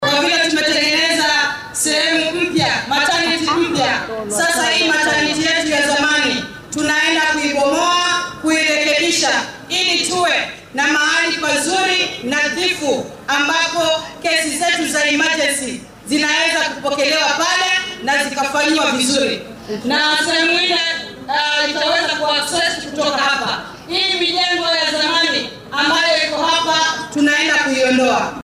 Guddoomiyaha ismaamulka Kwale , Fatuma Maxamad Achani ayaa si rasmi ah u dhagax-dhigtay dhisidda qaybta la tacaalidda xaaladaha degdegga ee isbitaalka guud ee deegaanka Mwambweni. Barasaabka oo munaasabaddaasi hadal kooban ka jeedisay ayaa tidhi.